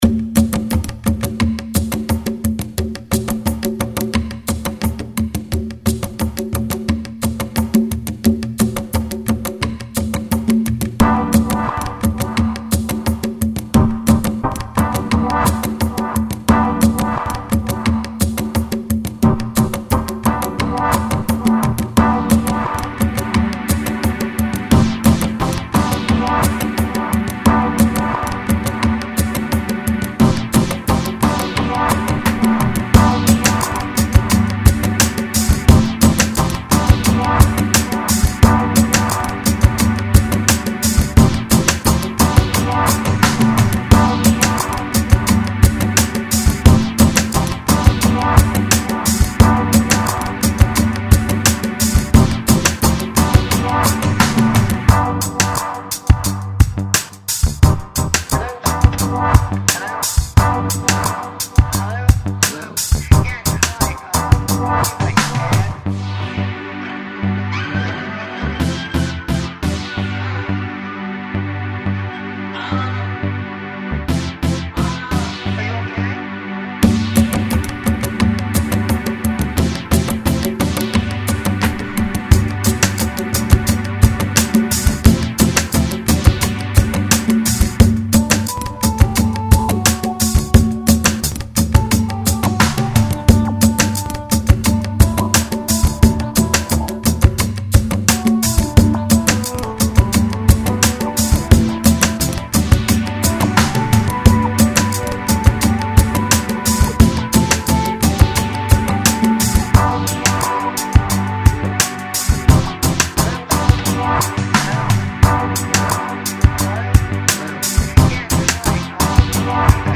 world music - chill - tempo - repetitif - voix